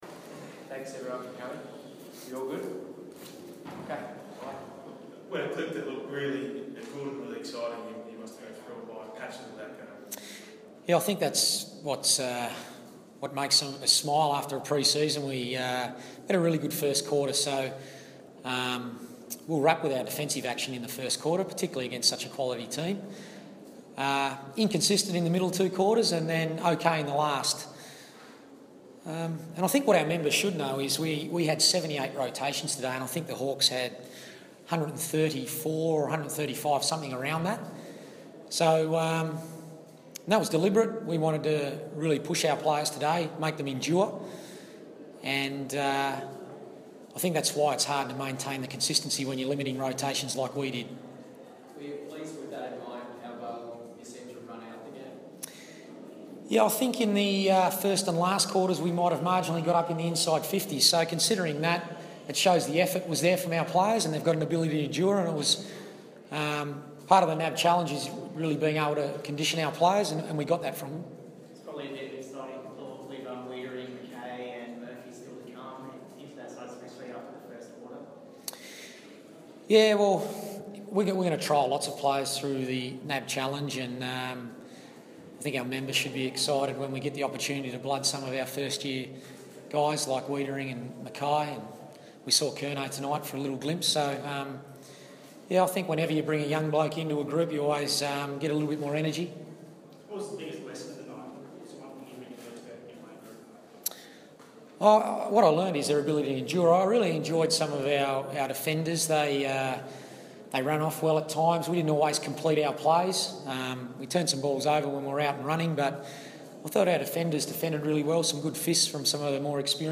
NAB 1 post-match press conference
Coach Brendon Bolton speaks to the media after Carlton's 21 point loss to the Hawks at Aurora Stadium.